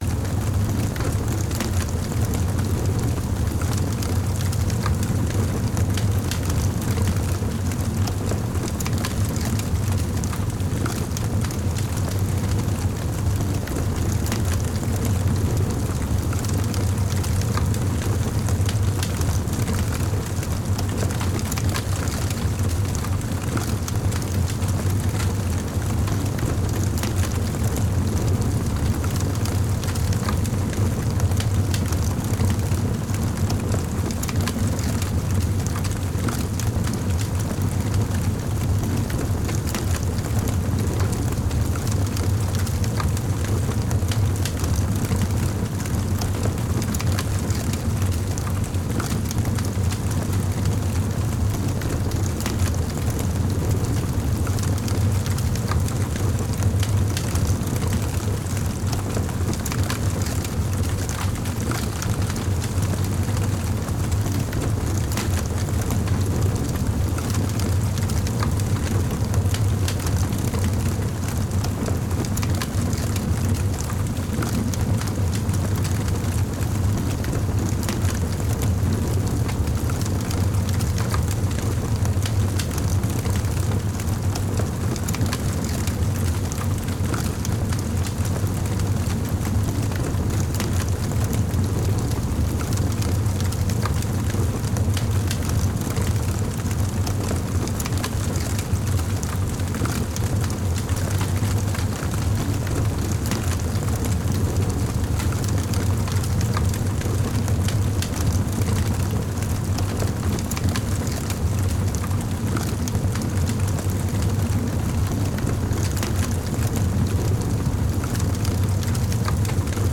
large_fire.L.wav